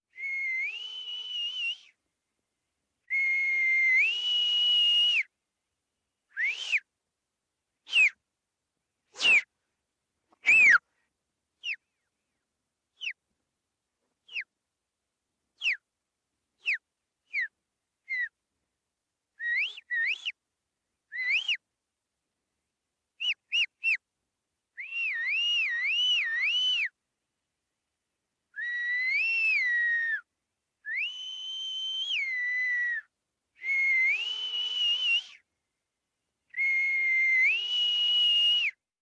Звуки свиста, свистков
Звук свиста человека подзывающего собаку